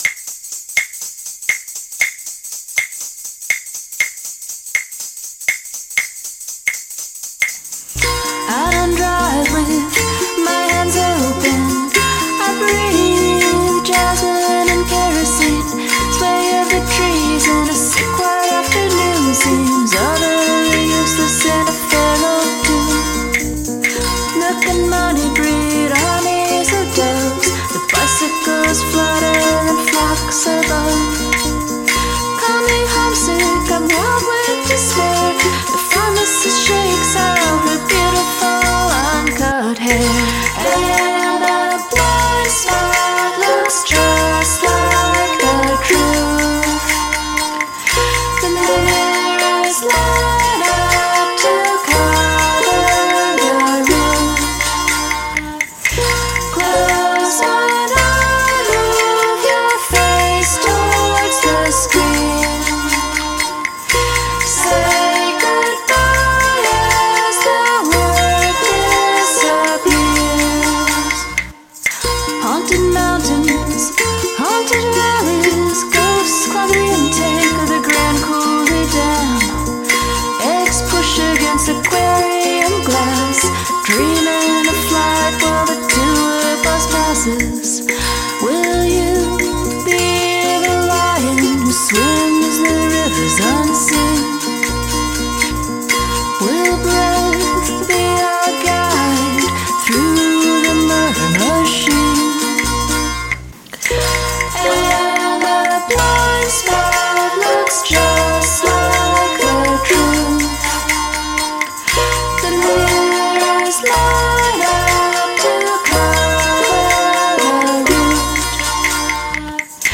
Hand percussion
love the zither!